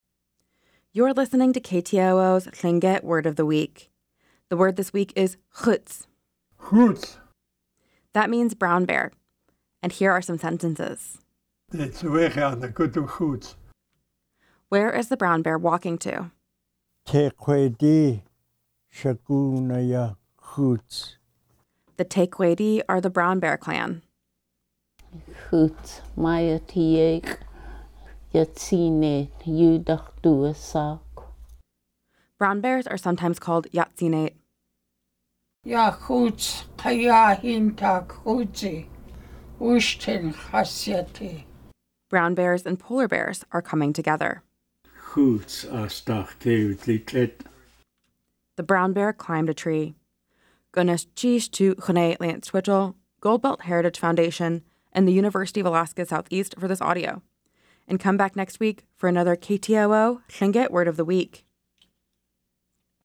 Each week, we feature a Lingít word voiced by master speakers.